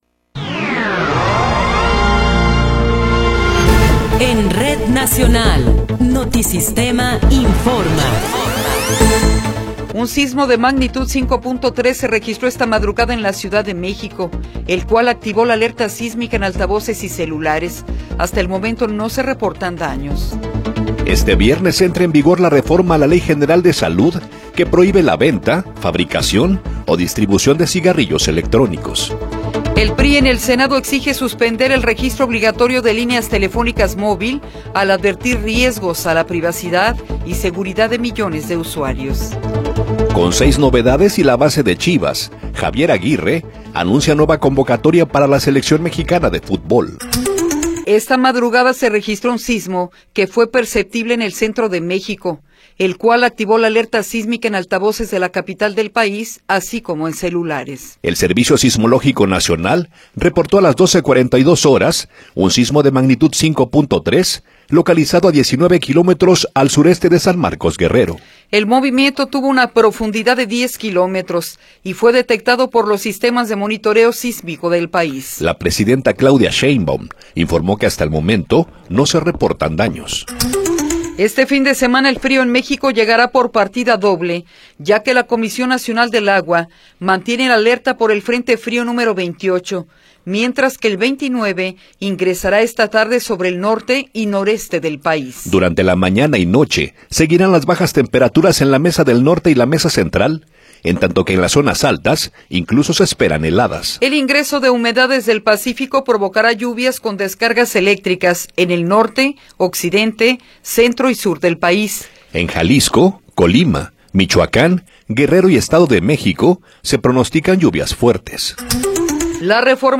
Noticiero 8 hrs. – 16 de Enero de 2026